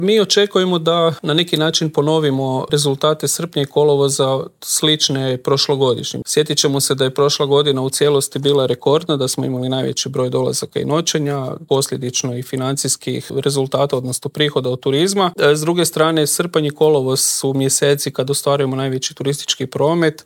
Brojke za prvih pola godine su izrazito dobre, a u Intervjuu tjedna Media servisa prokomentirao ih je direktor Hrvatske turističke zajednice Kristjan Staničić: